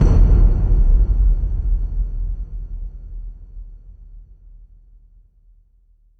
SouthSide Stomp (6) .wav